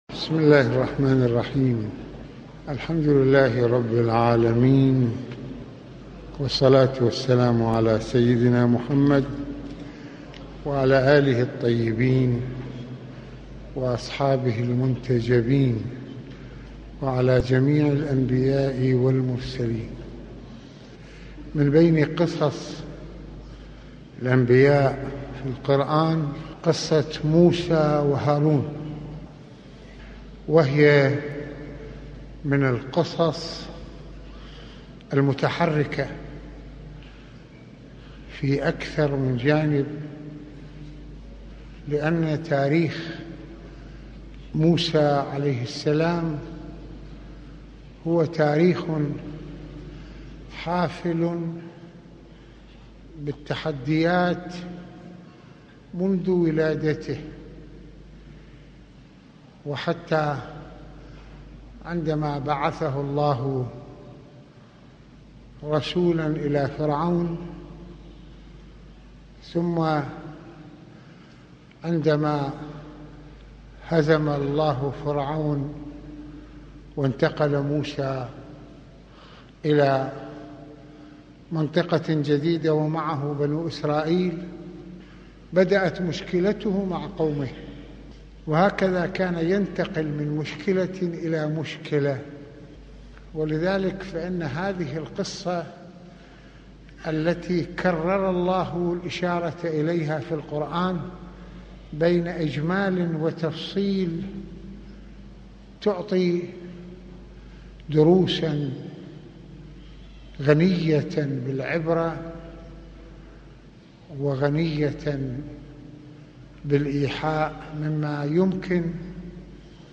- يتحدث سماحة المرجع السيد محمد حسين فضل الله (رض) في هذه المحاضرة عن قصة موسى وهارون وما حدث معه مع بني اسرائيل والدروس المستفادة من محطّاتها الغنية ومن أبرزها منام فرعون وذبحه للأطفال واستبقائه للنساء وطغيانه واستعلائه، وأهمية تربية النفس على التواضع والانسانية، فالناس متكاملون في عناصر قوتهم وعليهم أن يفيدوا منها ...